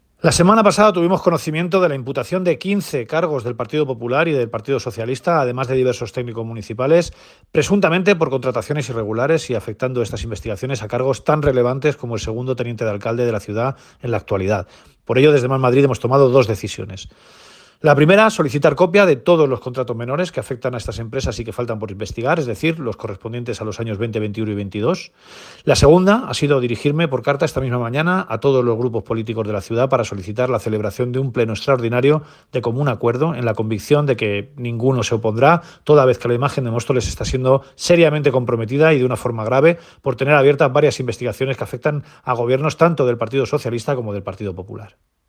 declaraciones-emilio-delgado-contrataciones.mp3